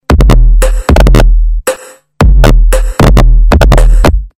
Категория: SMS рингтоны | Теги: SMS рингтоны, bass